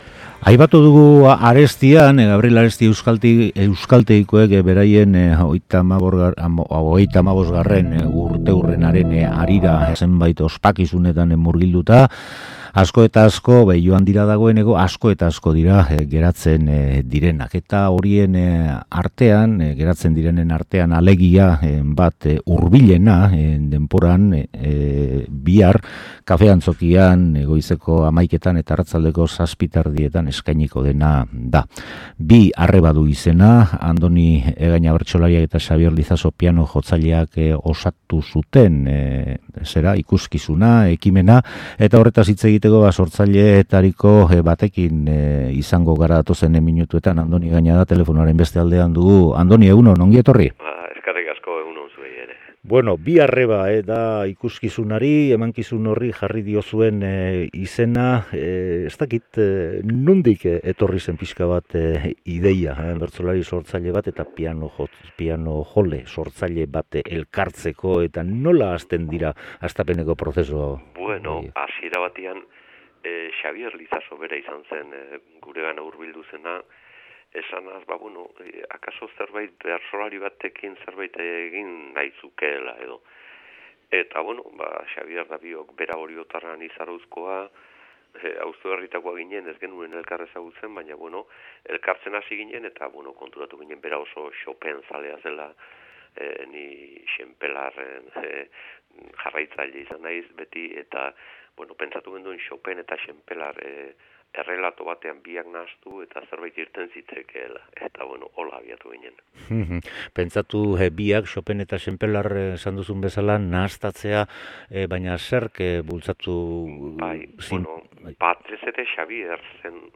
solasaldia
Hilaren 24an, astearterekin, izango da Bilboko Kafe Antzokian, goizean zein arratsaldean. Gaurkoan, Andoni Egaña bertsolariak gure galderei erantzun die, entzulea kokatzeko asmoz.